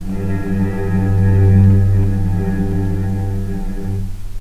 vc-G2-pp.AIF